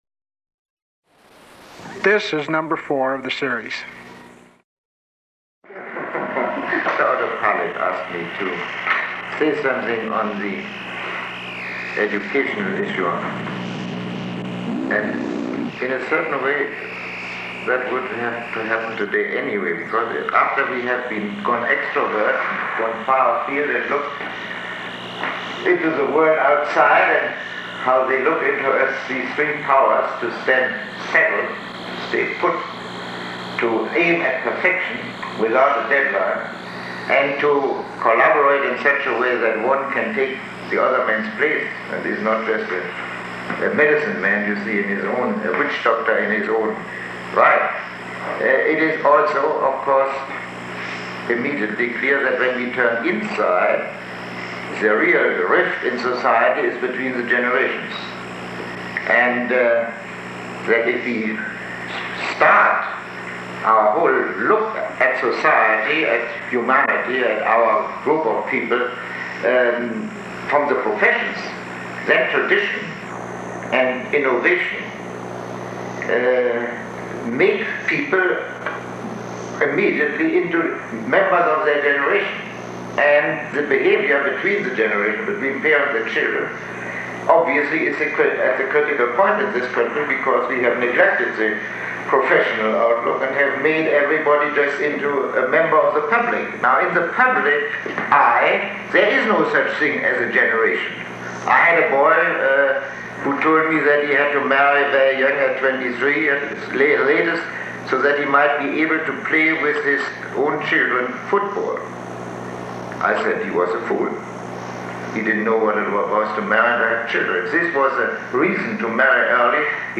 Lecture 04